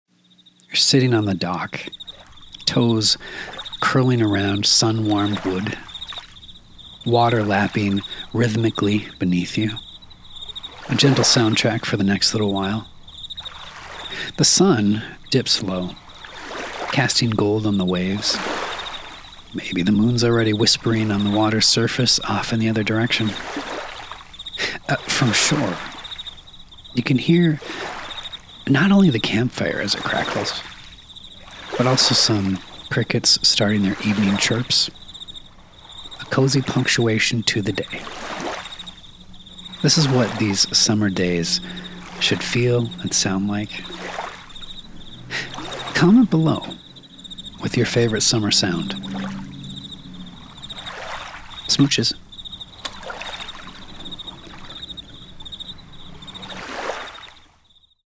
The Sounds on the Lake at Sunset | 🌀 Timeline Cleansers
A brief reprieve from it all, toes curled on the dock, the sunset, the moon nearby.
Timeline-Cleansers_-The-Sounds-of-a-Setting-Sun-on-the-Lake.mp3